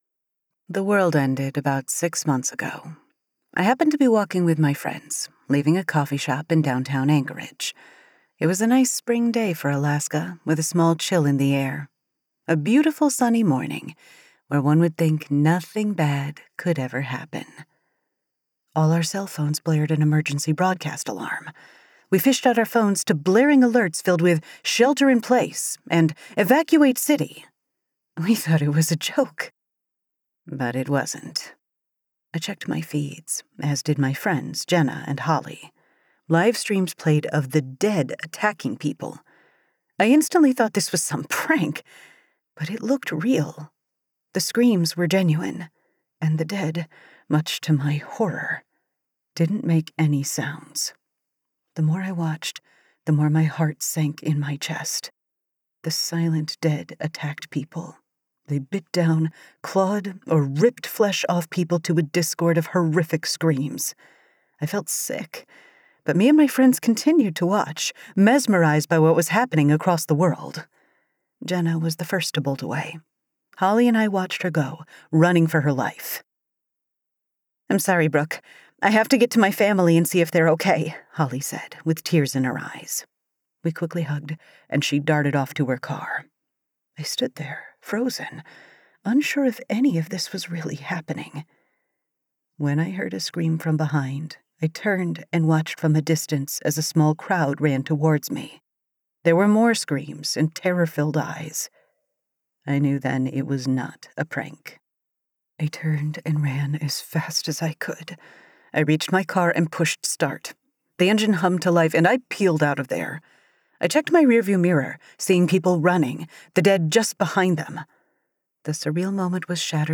Lawless Haven 1 & 2 Audiobook